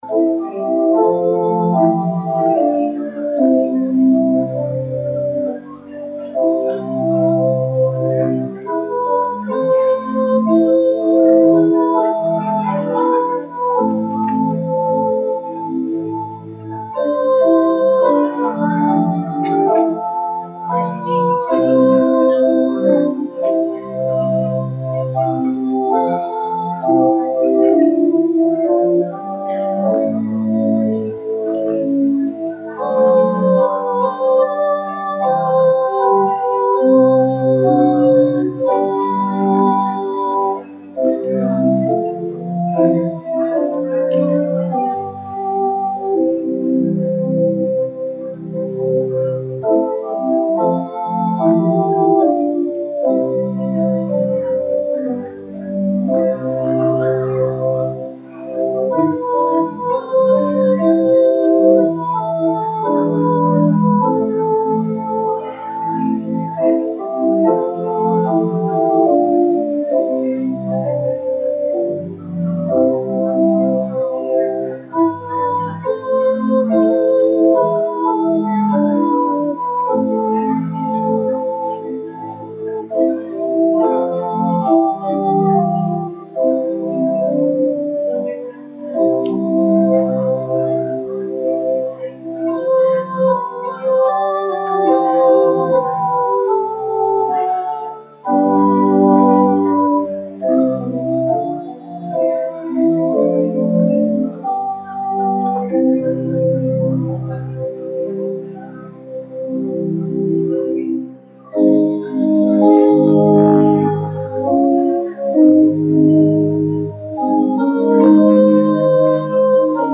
RUMC-service-Mar-19-23-CD.mp3